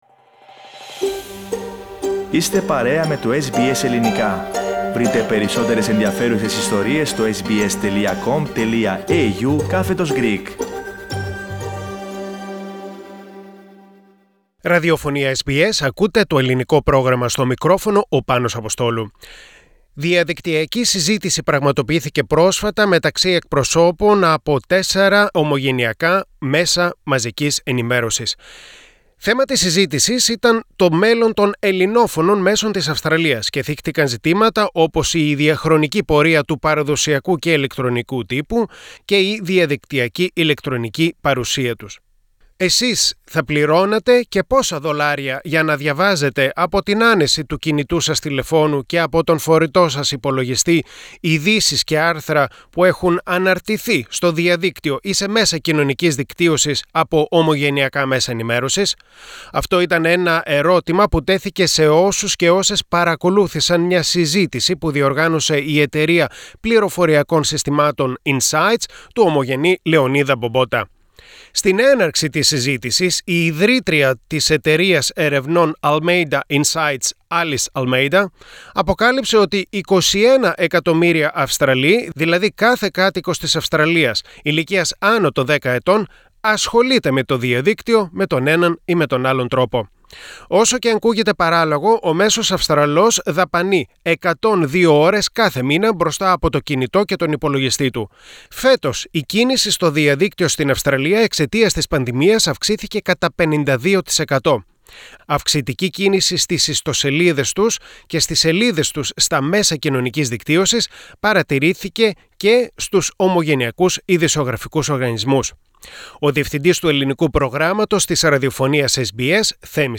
The future of Greek Australian media was in the spotlight during a special panel discussion with some of the industry’s leading publishers and editors.